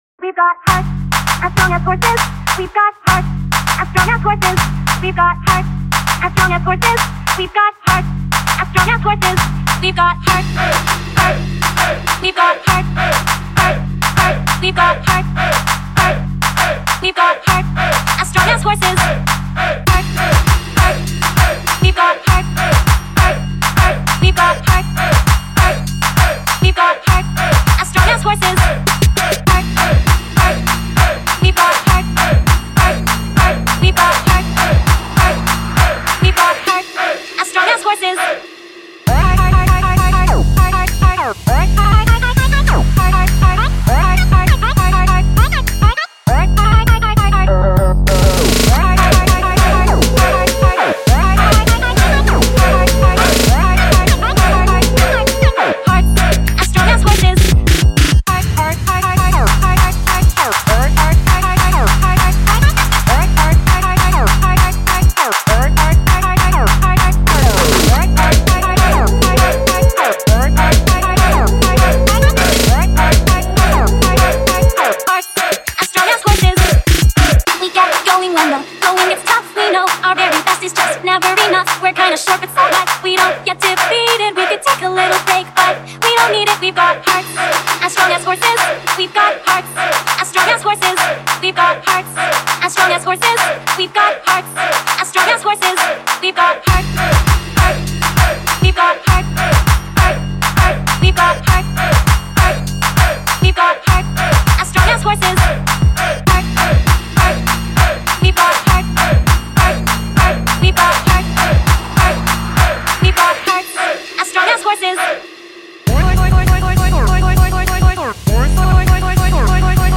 crunk crew